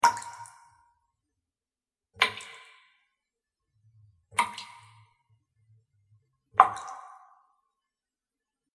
環境音 （38件）
ぴちょん連続.mp3